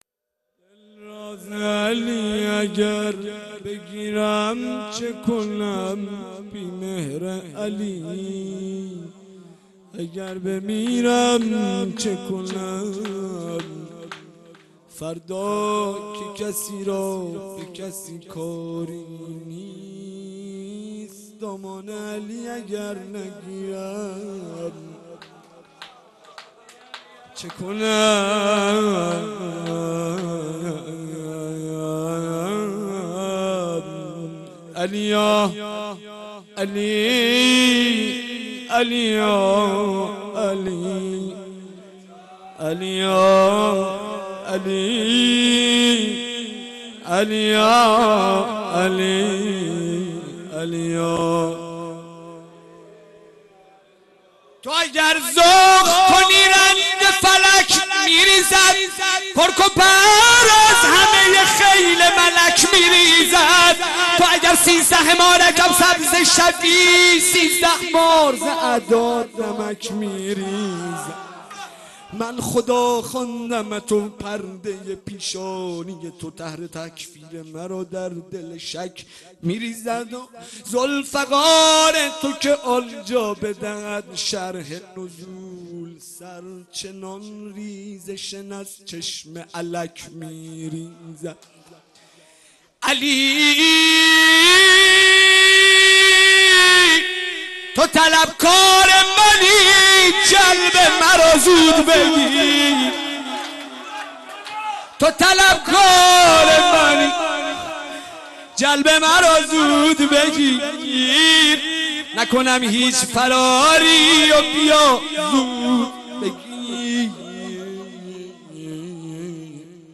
مناجات امام زمان